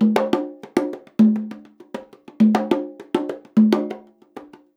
100 CONGAS15.wav